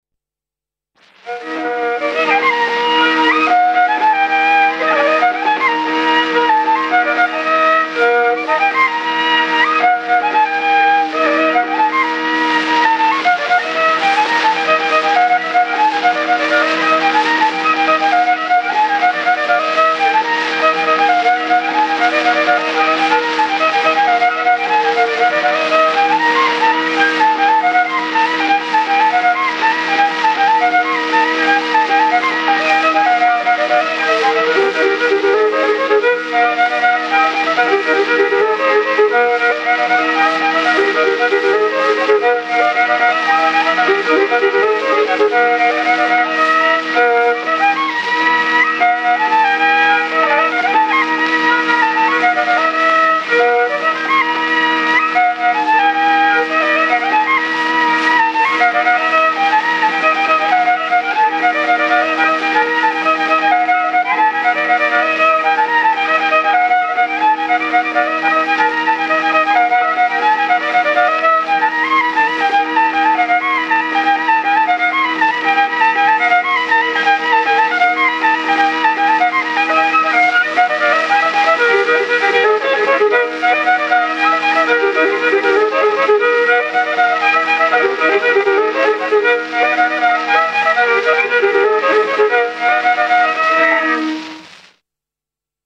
I viiul
burdoonsaade